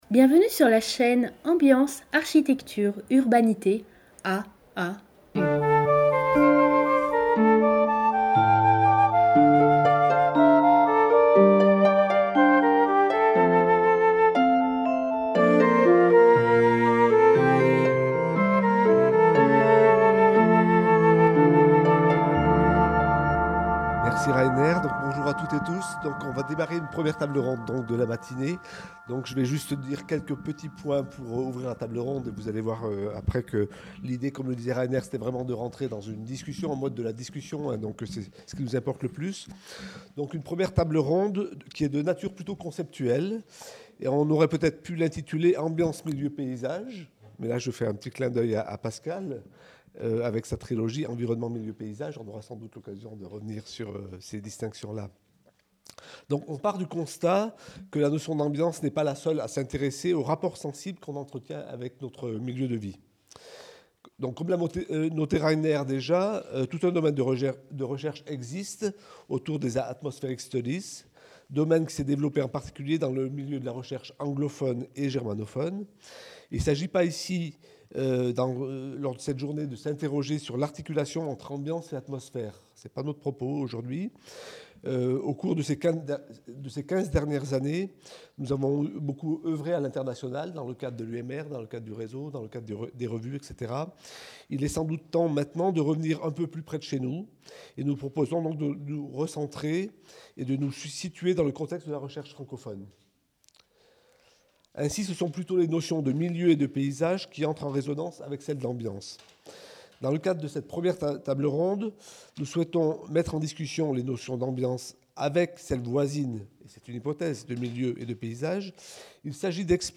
Table ronde 1 : Ambiance, Milieu, Paysage | Canal U